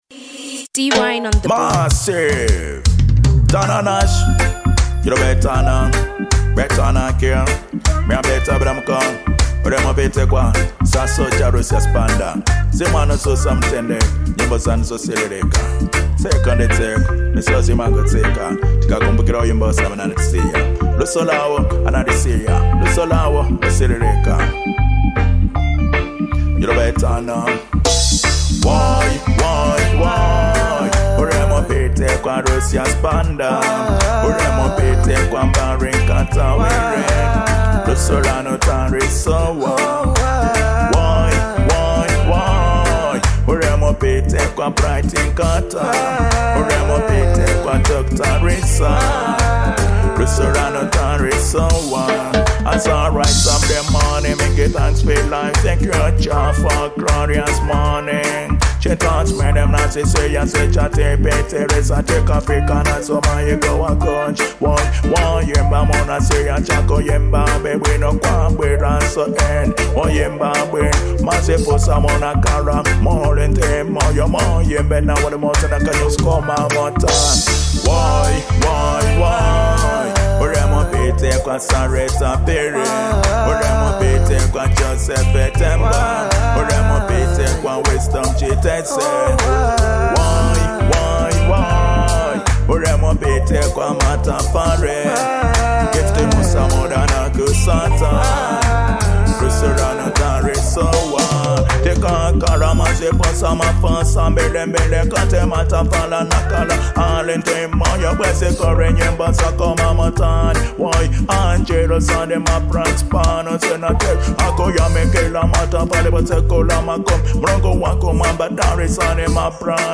Genre : Reggie Dancehall